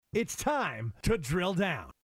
Tags: humor funny sound effects sound bites radio